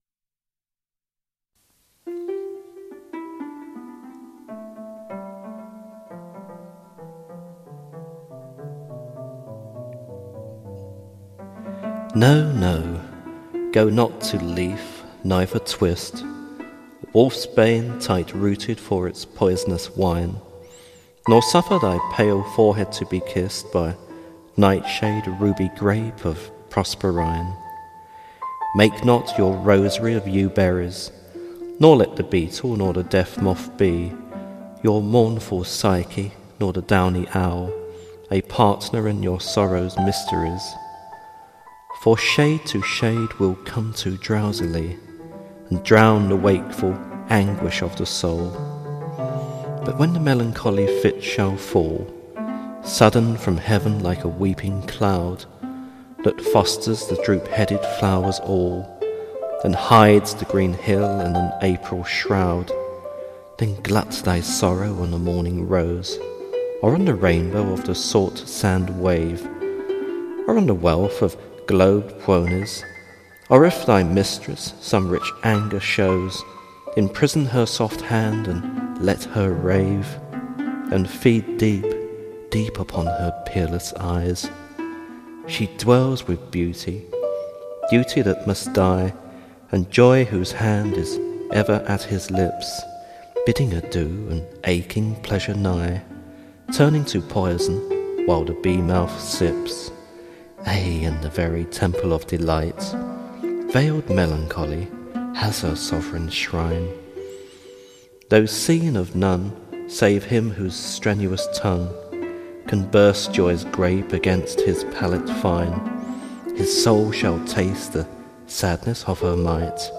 Recited by and with original music